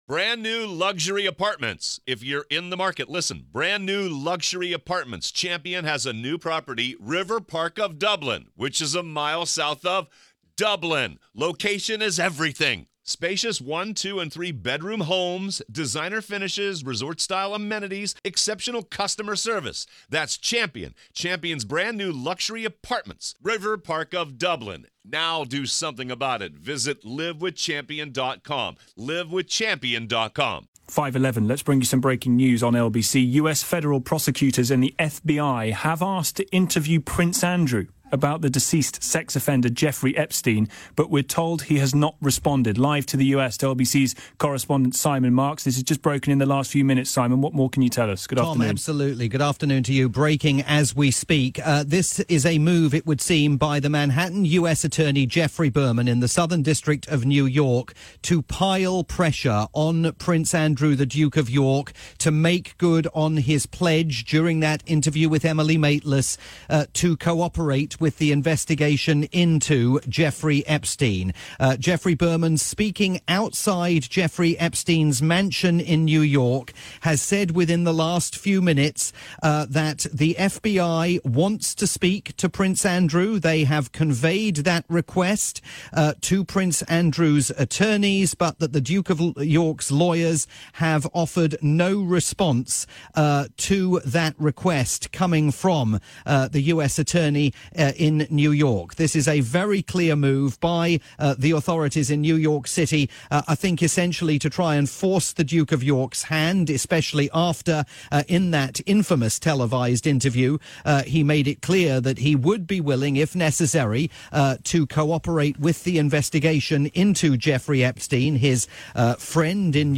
live report on the breaking news from Washington for LBC in the UK.